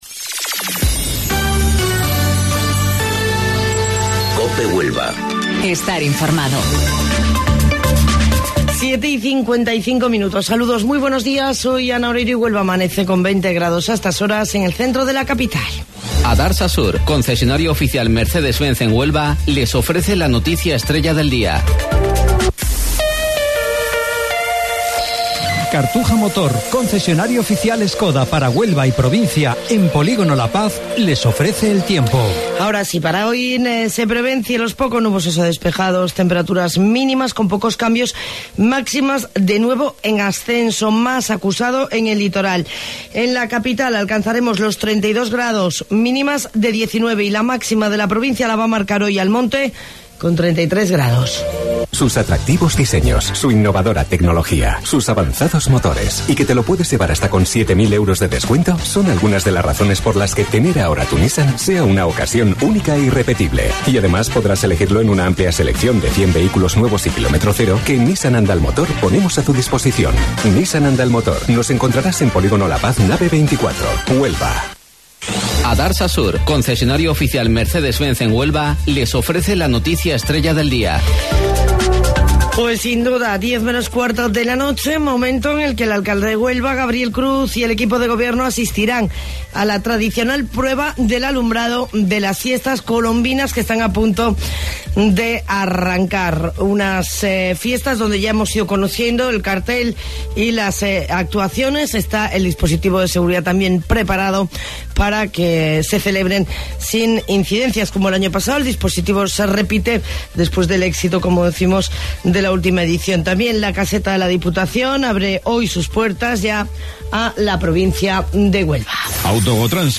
AUDIO: Informativo Local 07:55 del 29 de Julio